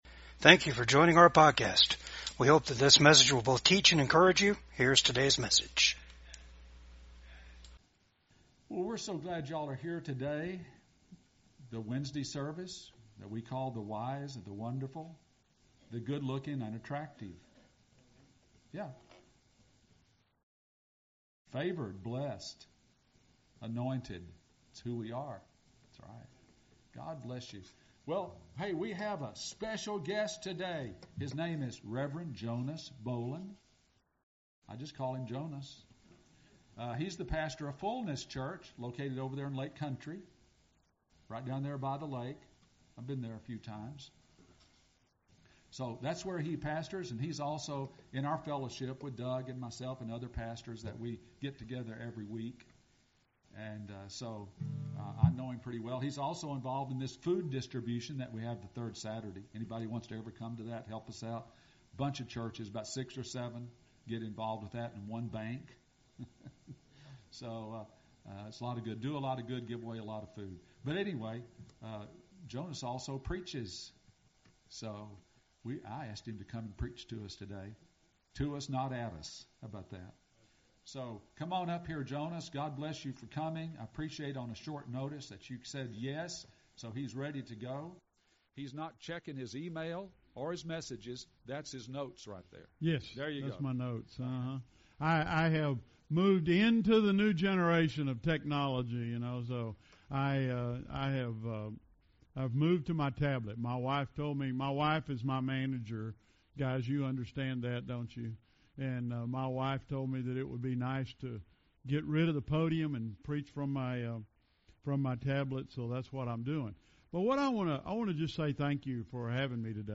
Psalms 16:11 Service Type: VCAG WEDNESDAY SERVICE ALL OF GOD'S FULLNESS IS AVAILABLE TO BELIEVERS